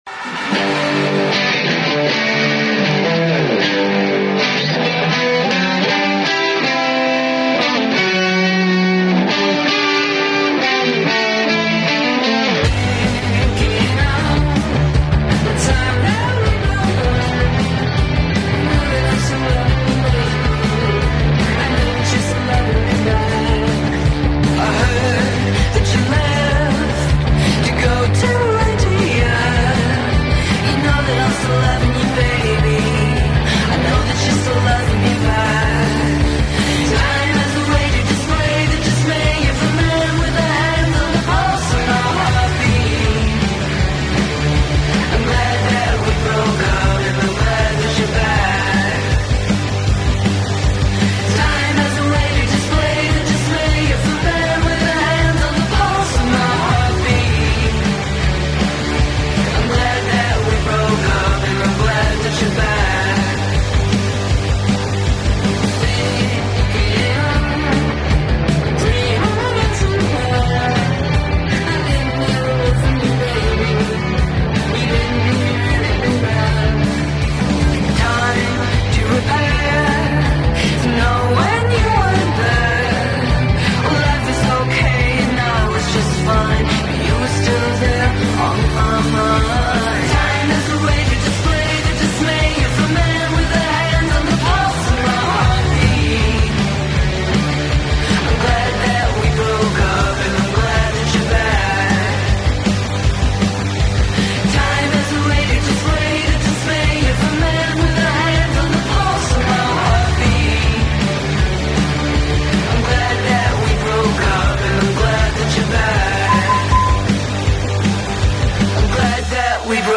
On June 3, 2021, WTSQ 88.1 FM in Charleston, West Virginia was over the moon to welcome the iconic Gary Numan to The Afternoon Show for a quick chat by phone!